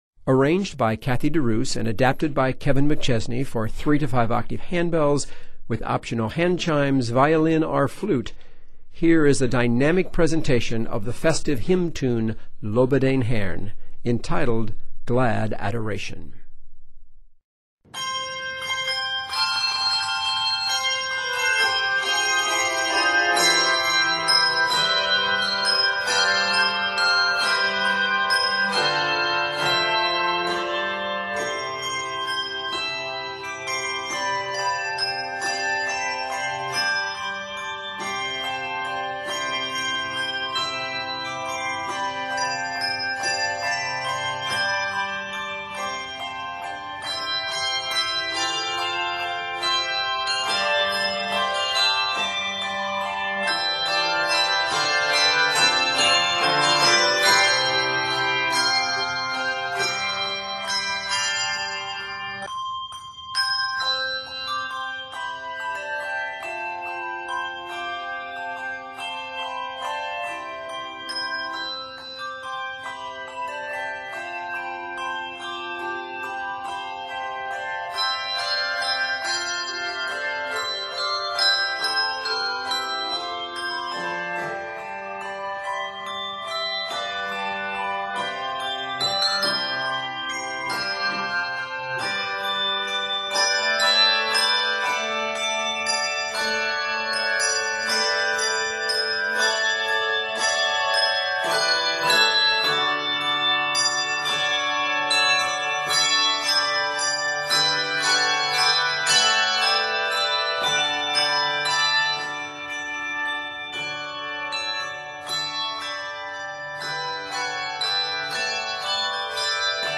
festive hymn
Set in C Major and D Major, measures total 94.